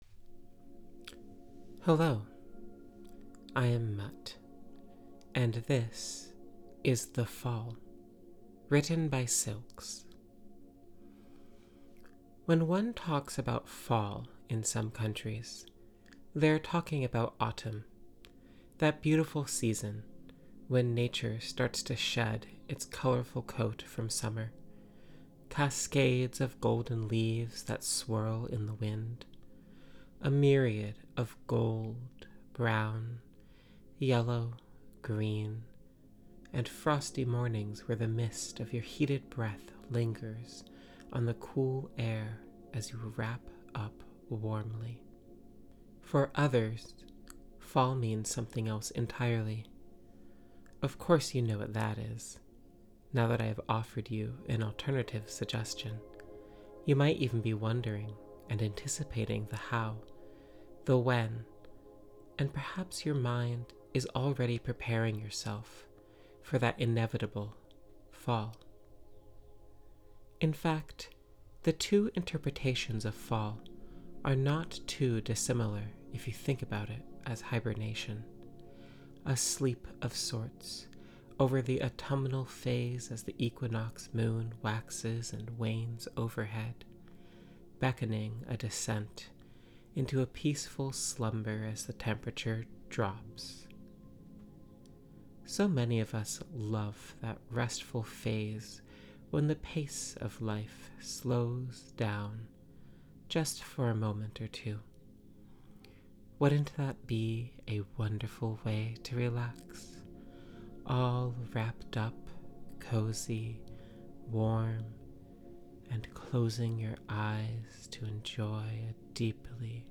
A guided meditation on a relaxing autumn walk